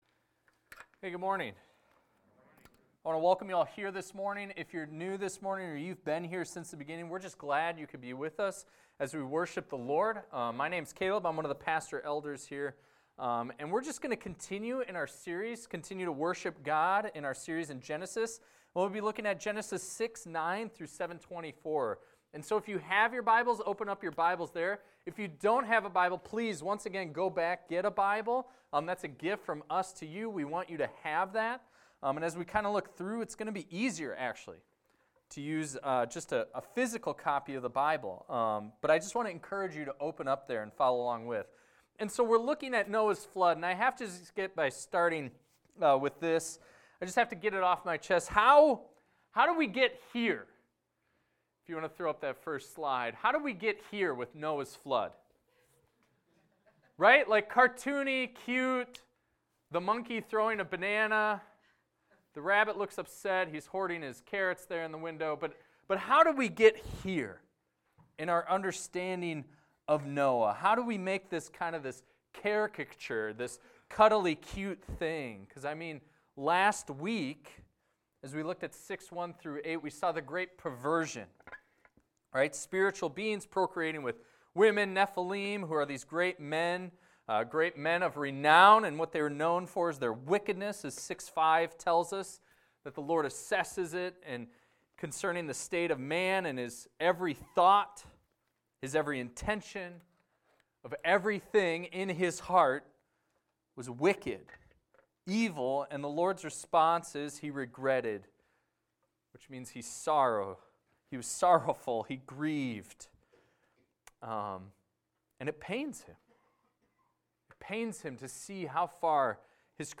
This is a recording of a sermon titled, "The Flood."